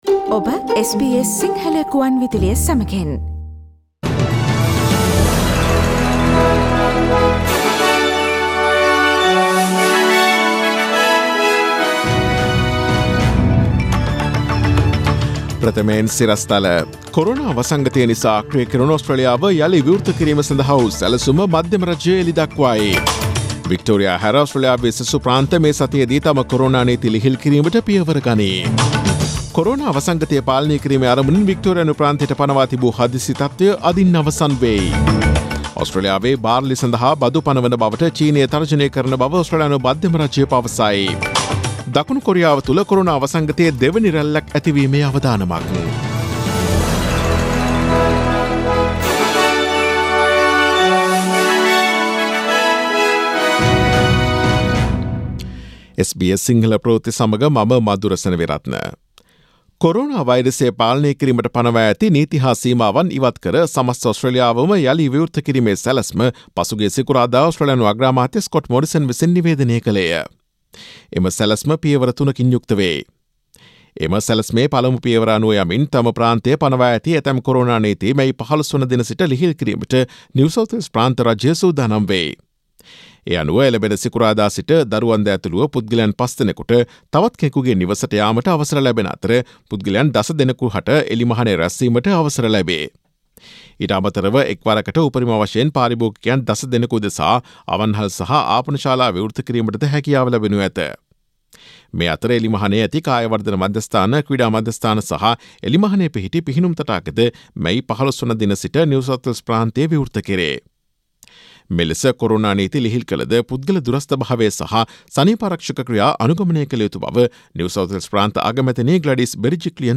Daily News bulletin of SBS Sinhala Service: Monday 11 May 2020
Today’s news bulletin of SBS Sinhala Radio – Monday 11 May 2020 Listen to SBS Sinhala Radio on Monday, Tuesday, Thursday and Friday between 11 am to 12 noon